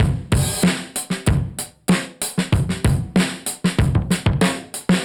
Index of /musicradar/dusty-funk-samples/Beats/95bpm/Alt Sound